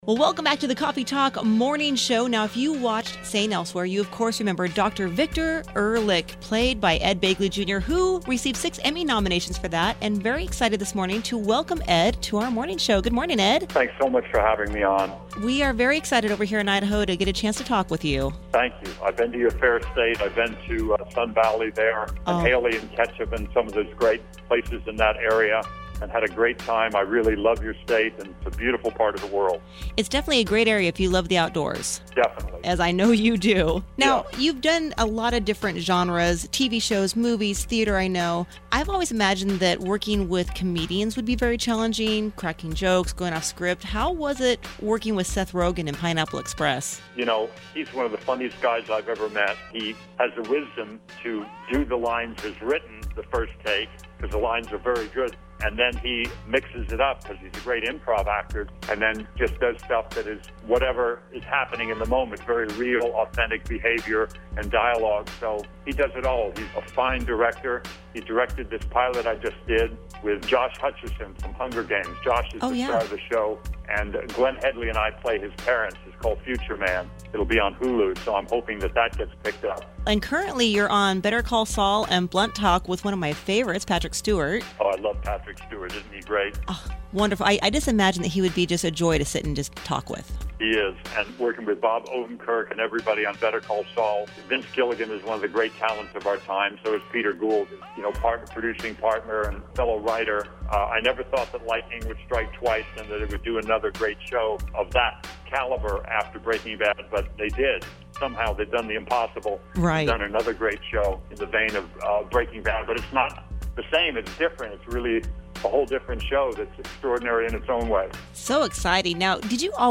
Ed Begley Jr. Interview - Pt. 1